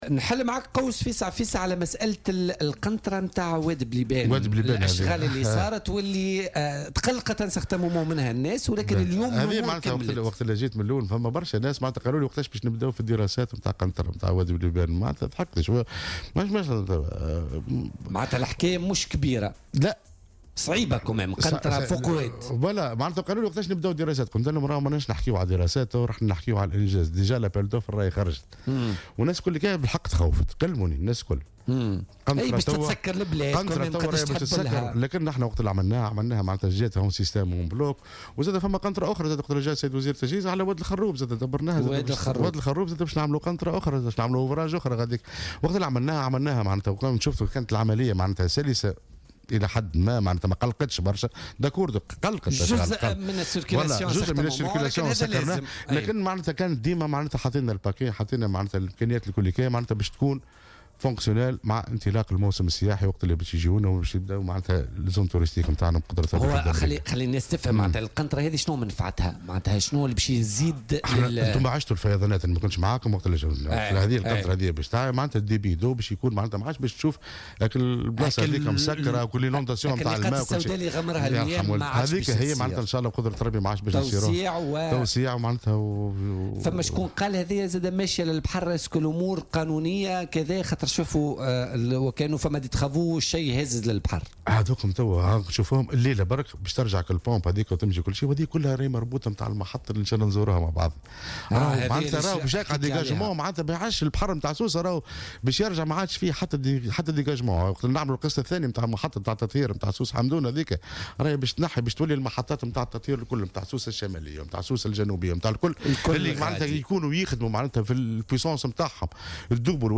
قال والي سوسة عادل الشليوي ضيف "بوليتيكا" اليوم الخميس، إن أشغال قنطرة وادي بليبان بسوسة متواصلة.